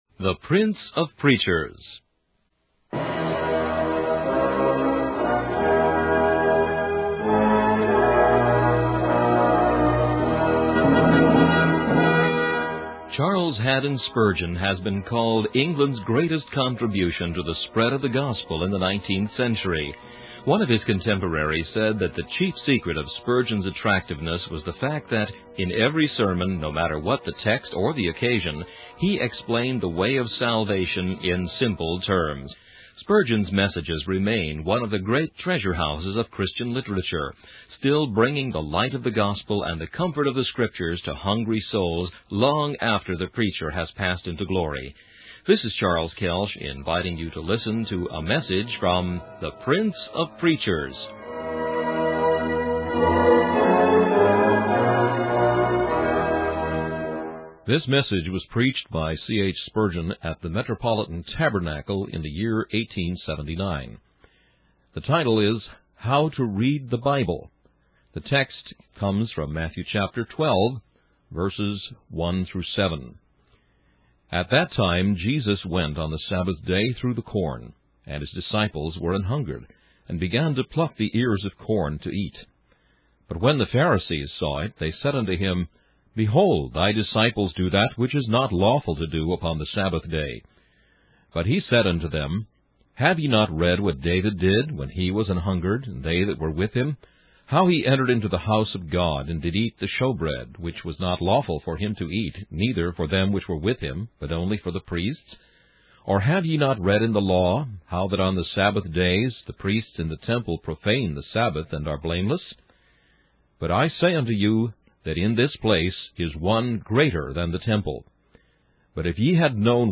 In this sermon, the preacher uses the analogy of mothers in the Beleric Isles who would hang their boys' dinners out of reach to teach them how to be good slingers. He compares this to how God puts precious truths in lofty places that we can only reach by 'slinging' at them through meditation. The preacher emphasizes the importance of meditating on the Word of God and how it can bring clarity and understanding to the mysteries of Revelation.